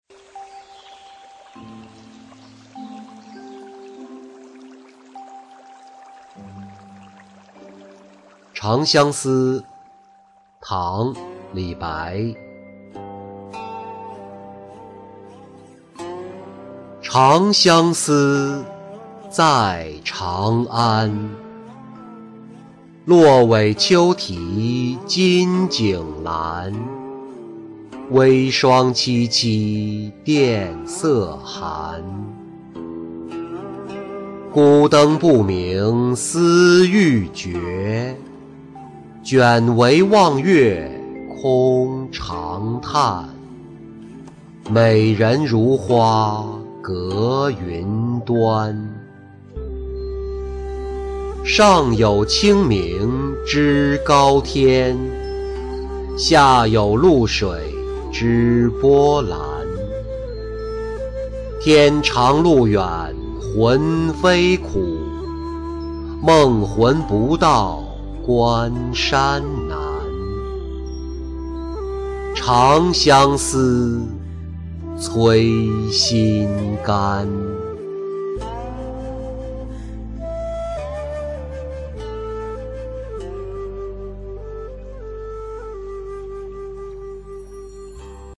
独觉-音频朗读